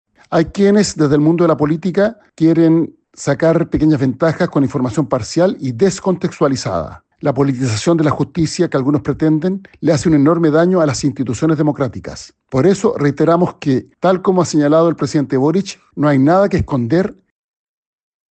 Por su parte, el ministro del Interior y vicepresidente de la República, Álvaro Elizalde, respaldó las palabras de la vocera, y enfatizó que el Ejecutivo suscribe los dichos del Presidente sobre que “no hay nada que esconder”.